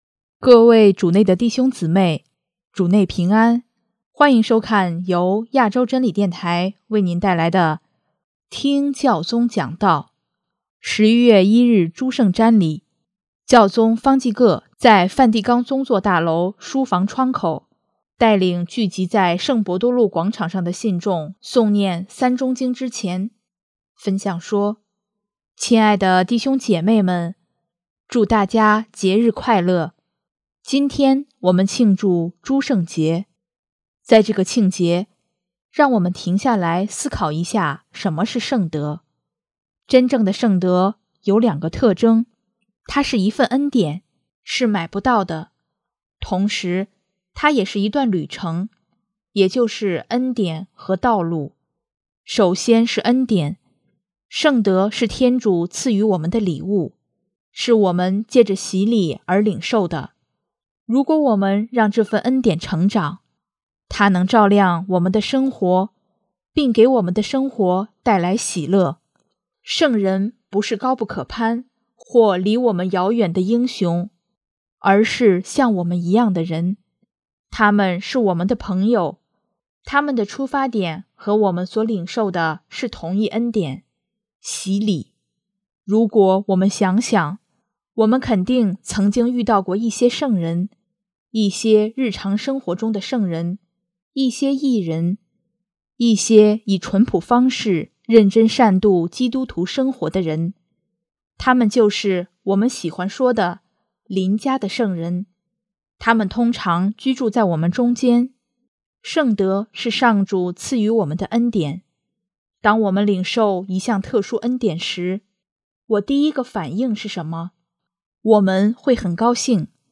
【听教宗讲道】|圣人不是高不可攀，他们是我们的朋友
11月1日诸圣节，教宗方济各在带领圣伯多禄广场上的信众诵念《三钟经》之前，分享说：